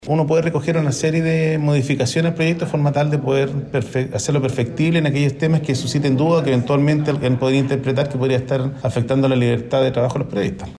El senador PPD, Pedro Araya, principal autor del proyecto, dijo que éste proyecto no innovará sobre sanciones que puedan recaer sobre periodistas, recordando que ya existen en otras leyes.